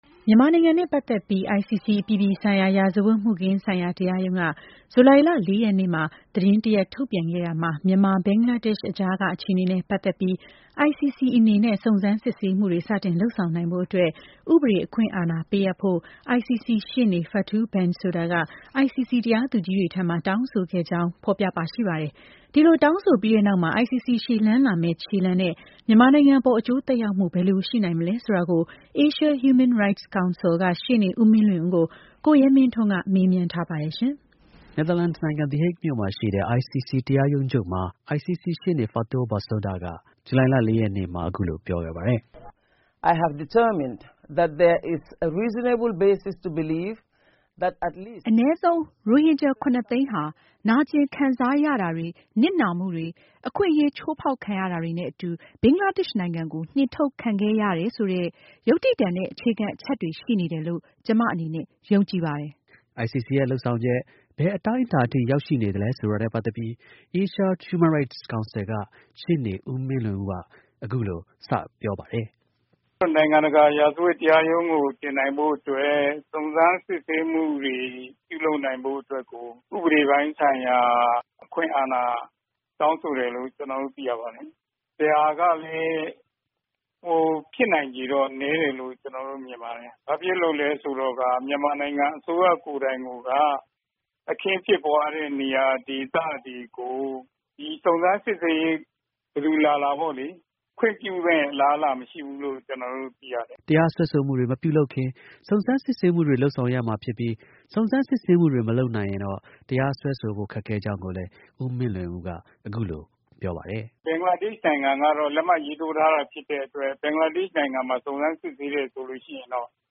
နယ်သာလန်နိုင်ငံ၊ The Hague မြို့မှာ ဇူလိုင် ၄ ရက်နေ့က ICC ရှေ့နေ Fatou Bensouda က အခုလို ပြောပါတယ်။